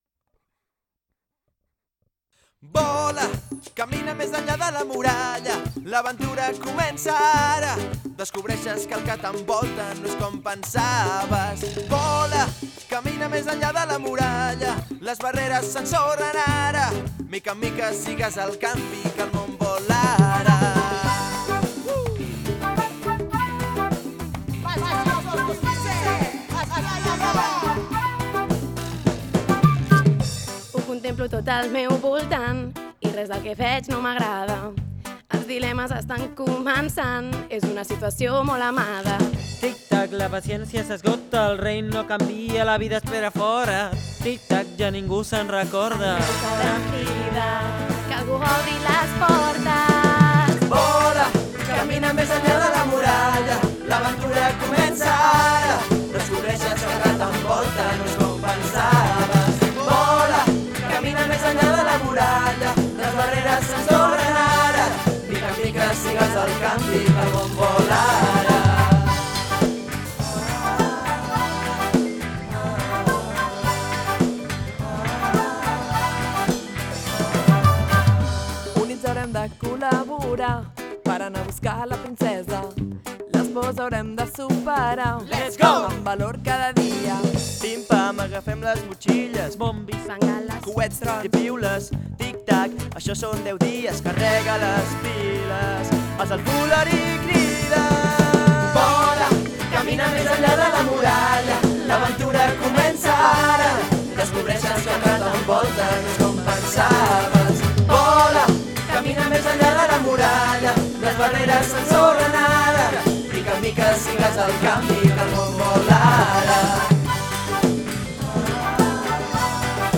Mas Gircós 2017